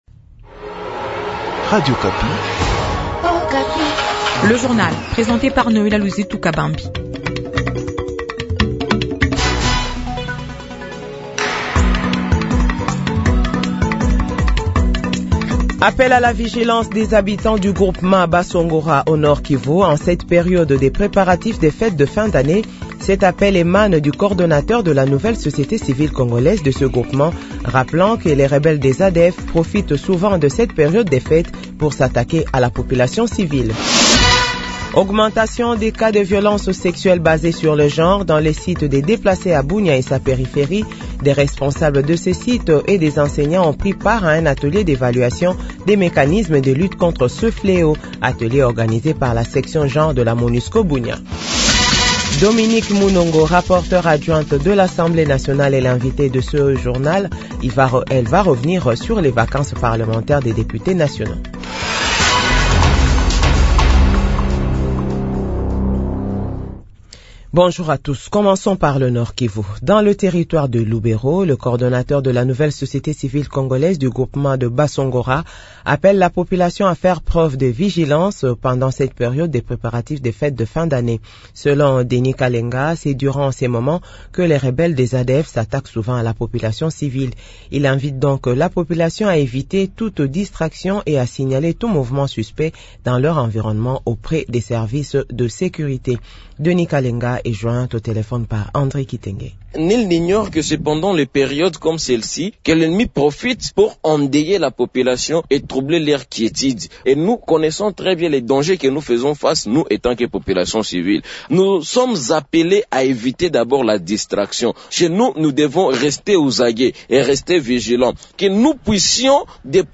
JOURNAL FRANÇAIS DE 8H00